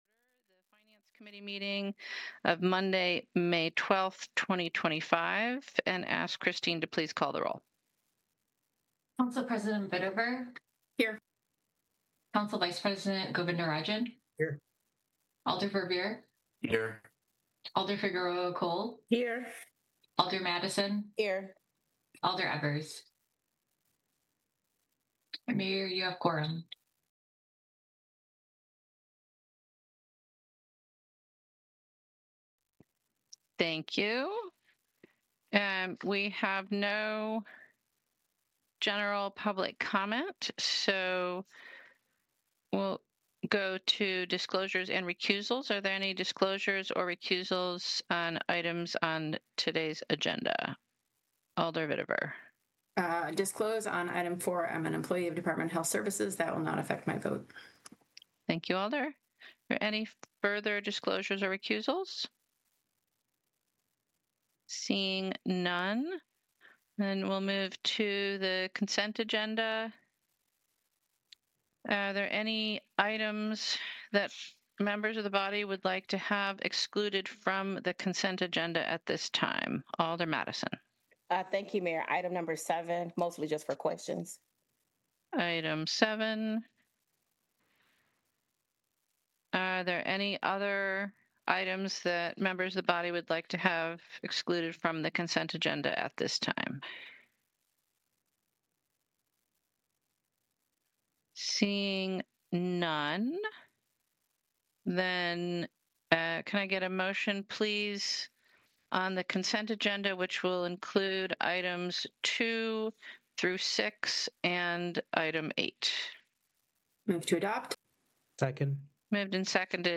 This is a podcast of City of Madison, Wisconsin Finance Committee meetings. The Finance Committee makes recommendations regarding budget amendments and other matters with significant fiscal implications during the year.